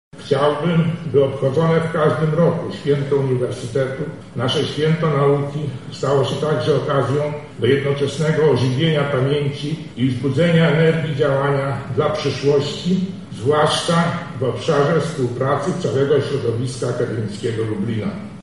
Uniwersytet Przyrodniczy obchodził swoje święto.